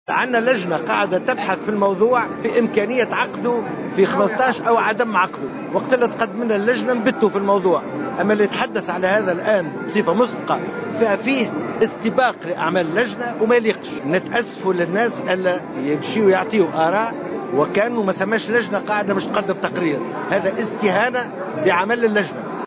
أكد أمين عام حركة نداء تونس الطيب البكوش و على هامش عقد اجتماع شعبي في الساحلين أن لجنة صلب الحزب لا تزال تعمل في تحديد تاريخ ال15 من جوان القادم موعدا لانعقاد المؤتمر الأول للحزب مشيرا إلى أن أي كلام غير تقرير اللجنة هو استباق لعملها.